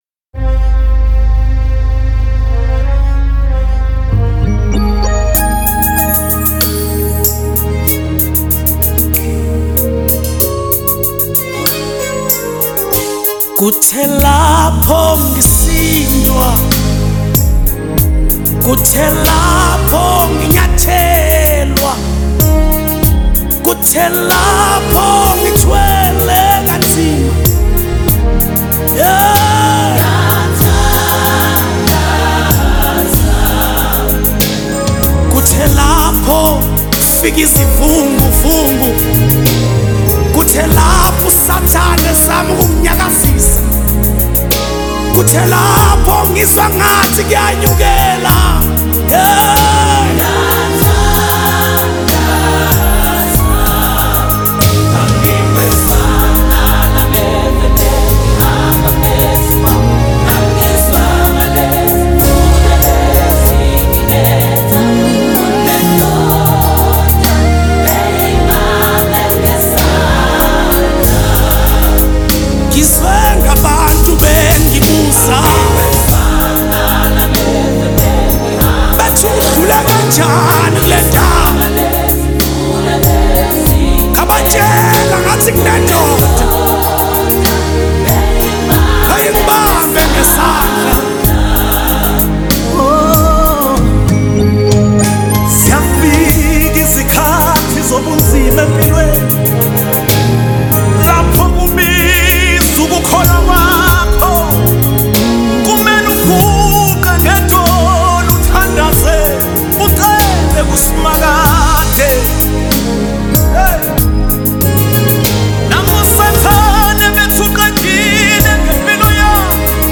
spirit-filled song
Genre : SA Gospel